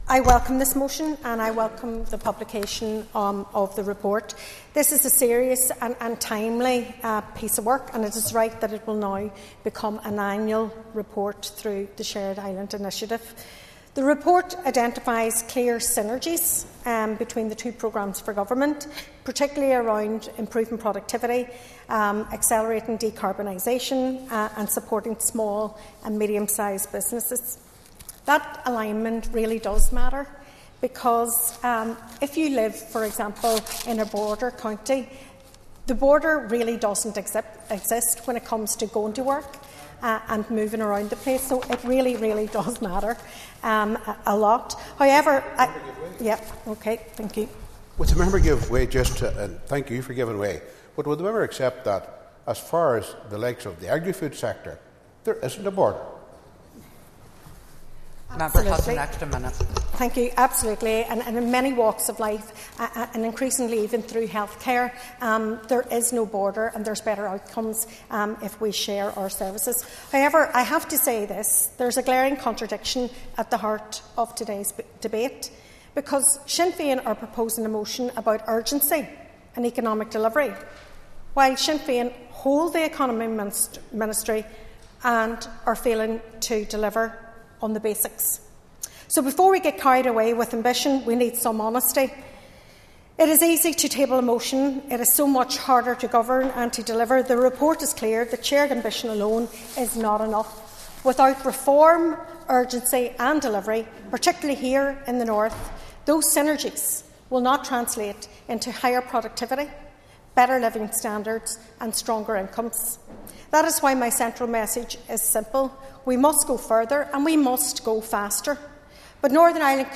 Speaking on a Sinn Fein motion noting the ESRI’s report Assessing Economic Trends on the Island of Ireland, the SDLP Economy Spokeserson welcomed the motion and the report, but said progress on developing the All Island Economy is not fast enough.
You can listen to Sinead McLaughlin’s full contribution here –